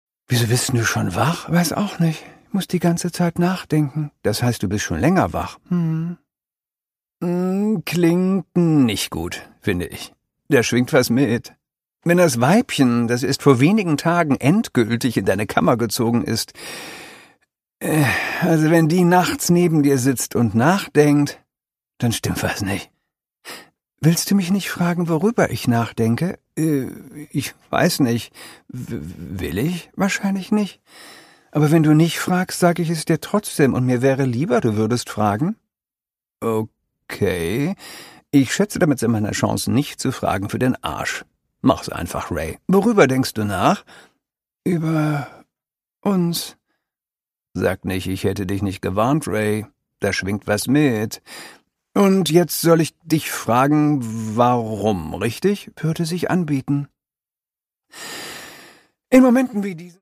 Produkttyp: Hörbuch-Download
Gelesen von: Christoph Maria Herbst